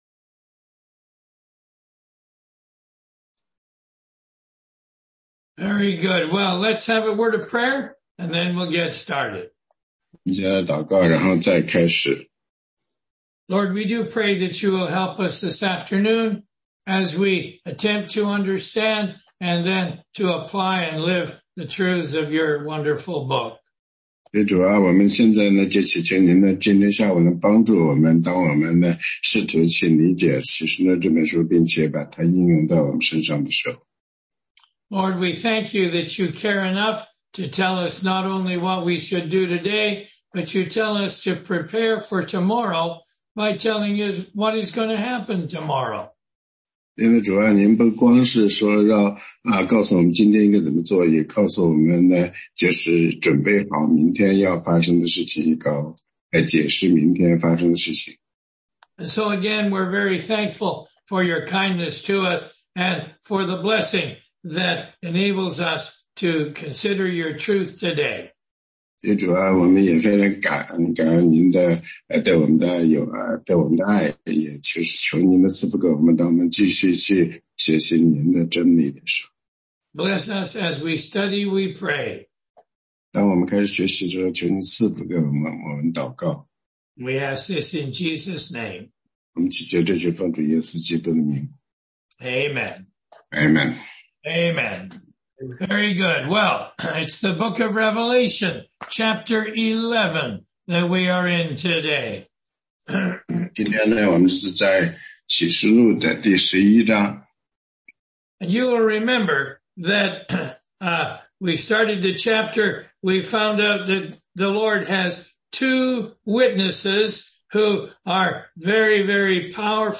16街讲道录音 - 启示录11章11节-12章17节
答疑课程